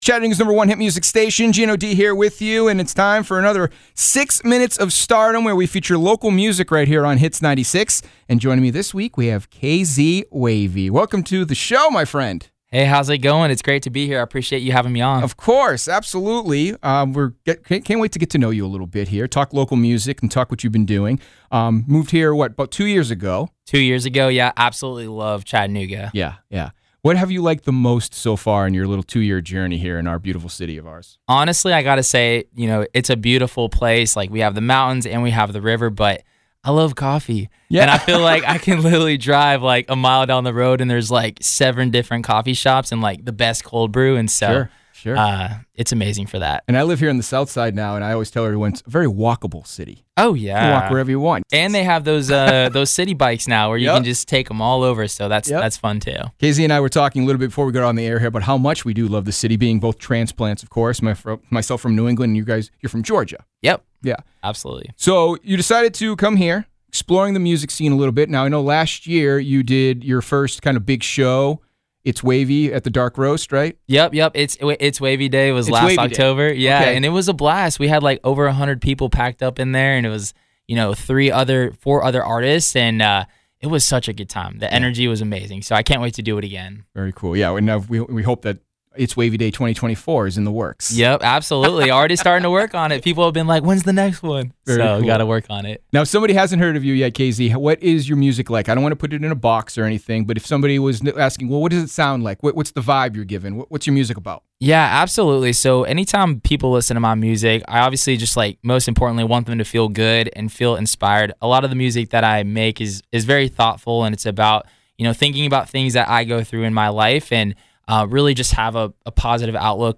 Full-Interview.wav